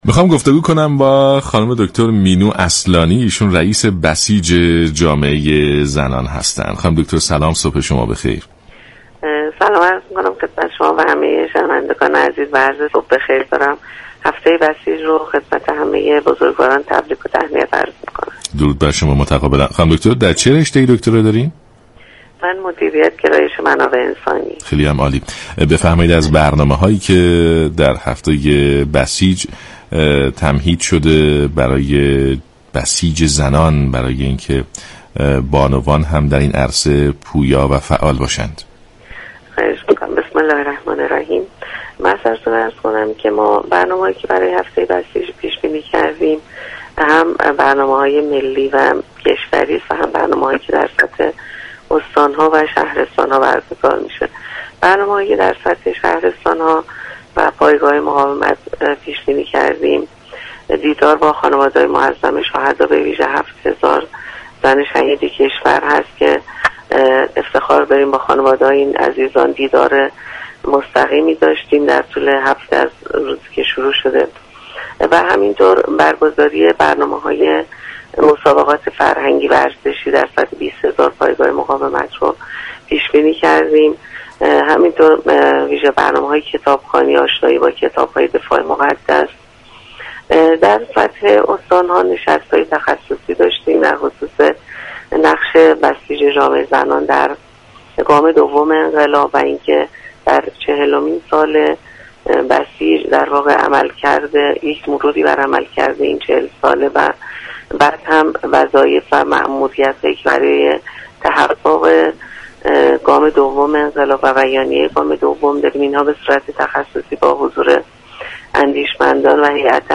رییس بسیج جامعه زنان در گفت و گو با رادیو ایران گفت: امسال استقبال جوانان از برنامه های فرهنگی و مسابقات چشمگیر بوده است.